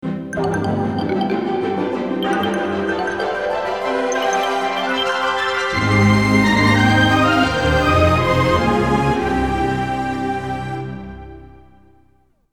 Puis la bande-annonce de la semaine.
TF1_Bandes_annonces.mp3